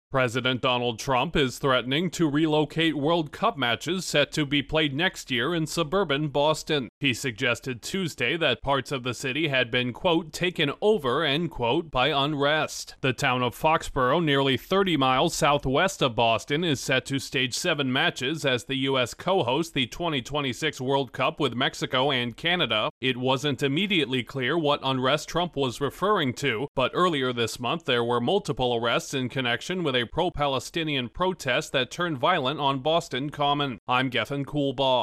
President Trump is once again suggesting to move FIFA-contracted World Cup matches next year. Correspondent